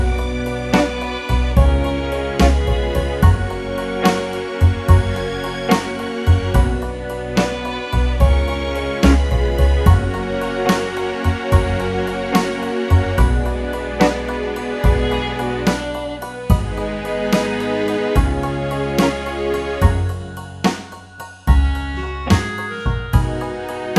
no Backing Vocals Oldies (Male) 2:36 Buy £1.50